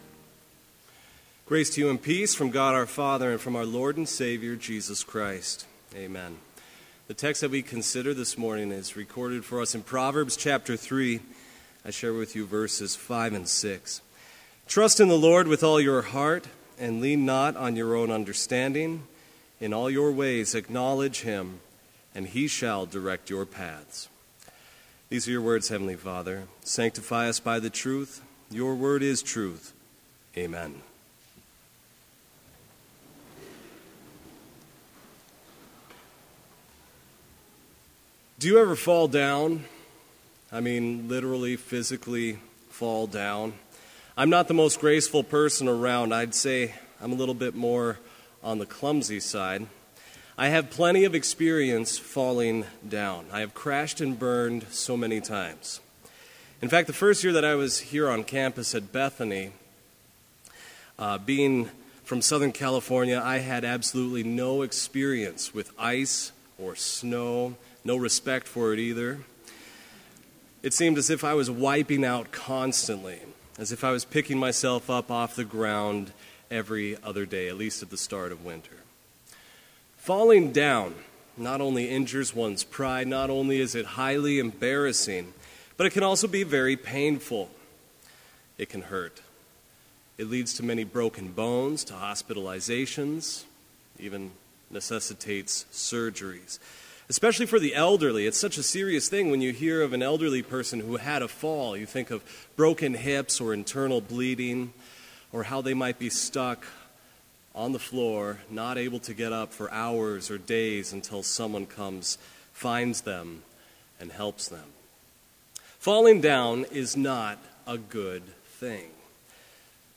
Complete service audio for Chapel - February 23, 2016